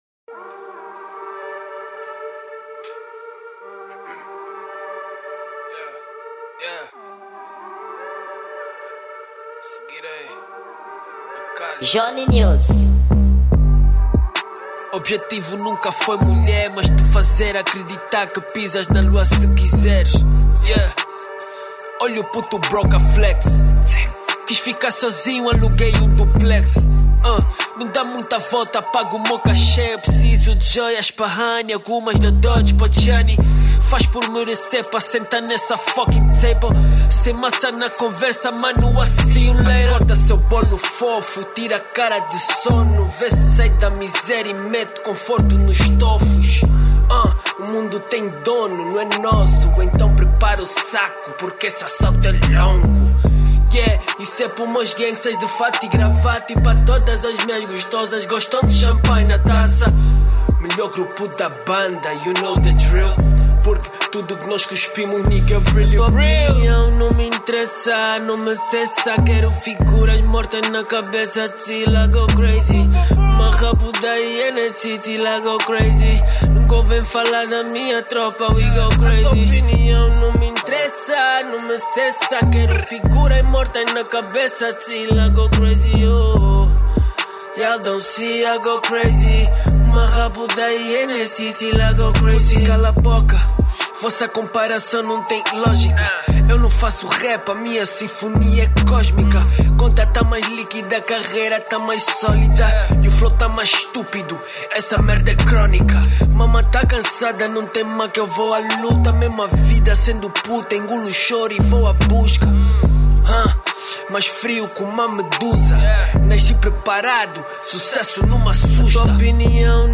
Gênero: Trap